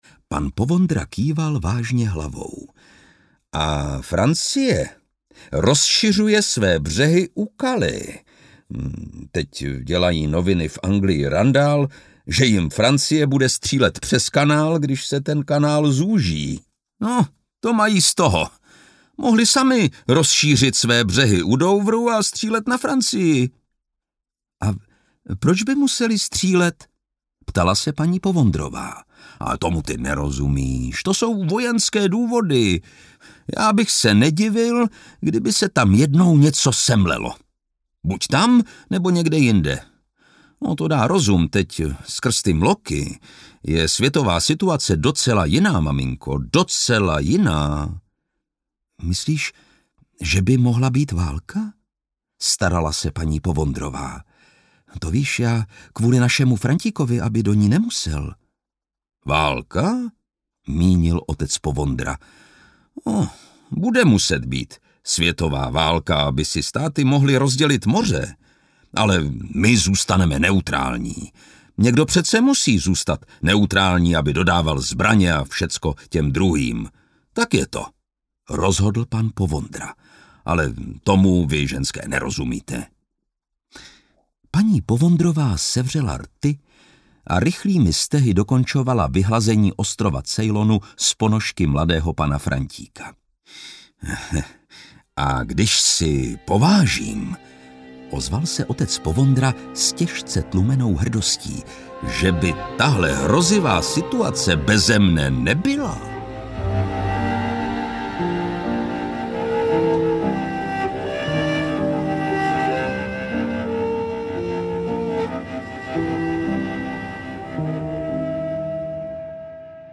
Válka s Mloky audiokniha
Ukázka z knihy
• InterpretLukáš Hlavica